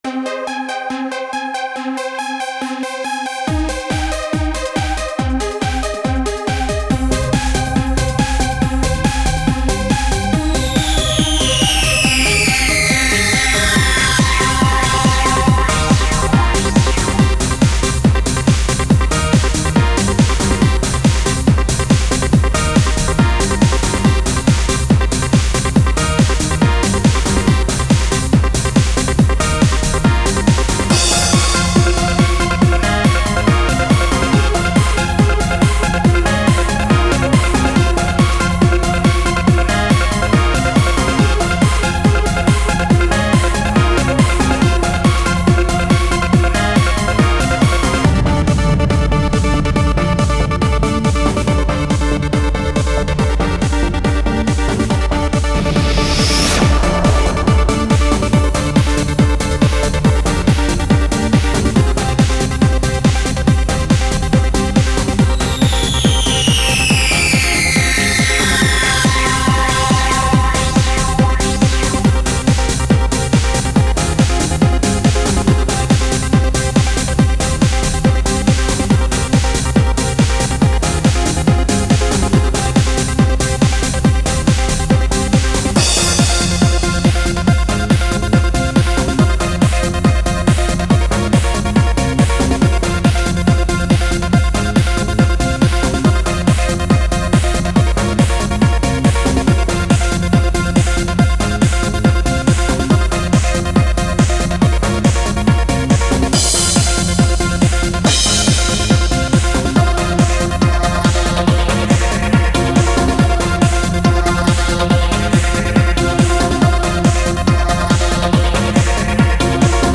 Techno χορευτική διάθεση